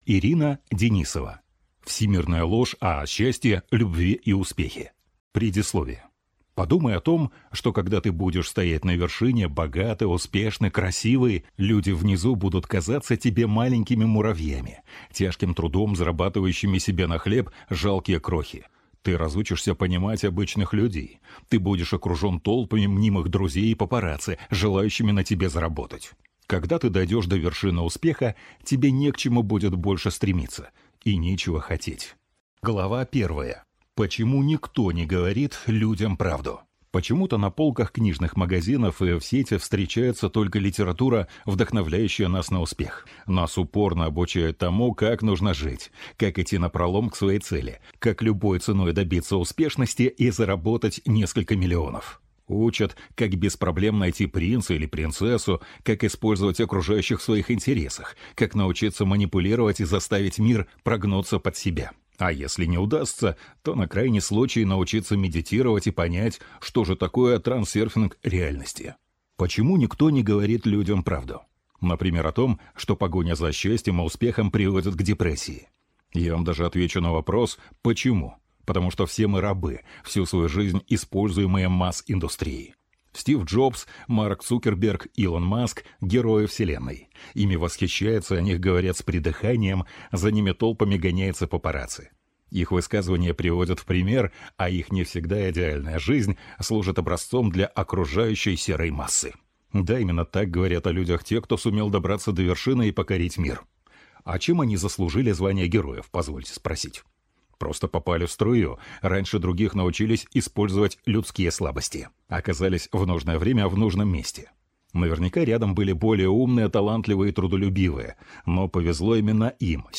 Аудиокнига Почему успех – это зло | Библиотека аудиокниг